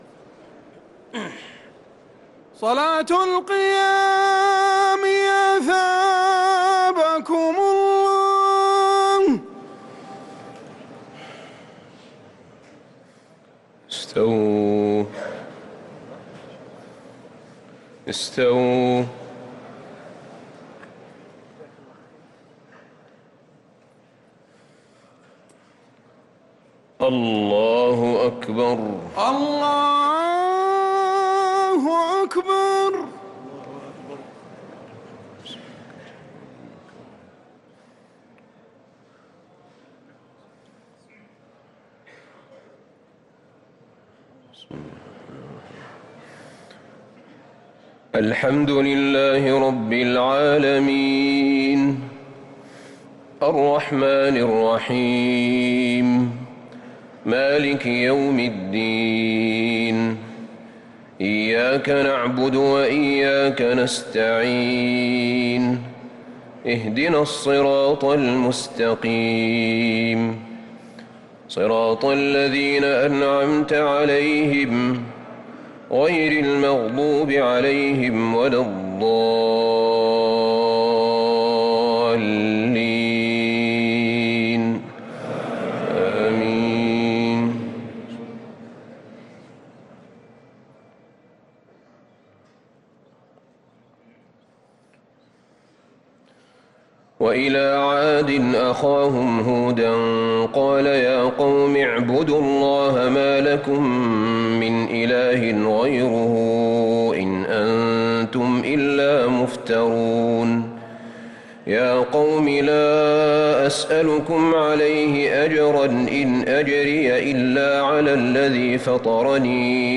صلاة التراويح ليلة 16 رمضان 1444 للقارئ أحمد بن طالب حميد - الثلاث التسليمات الأولى صلاة التراويح